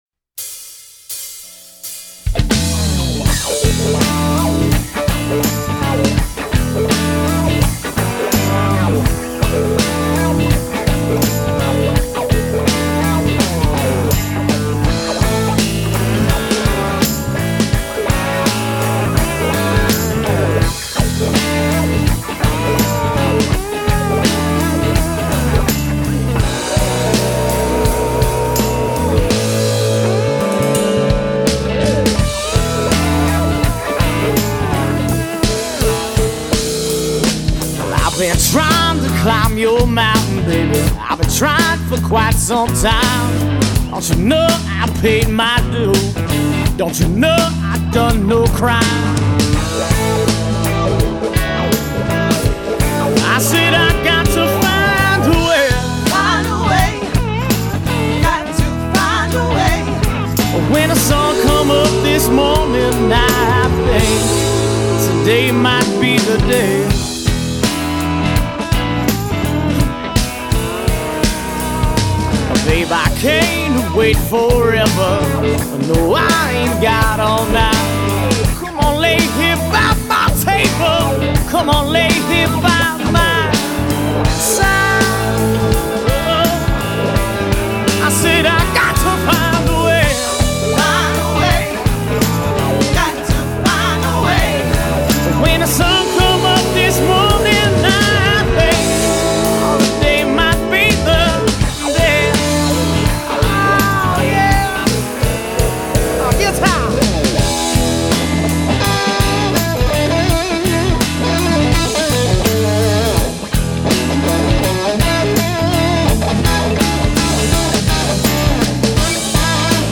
Genre: Blues / Southern Rock
Vocals, Guitar, Harmonica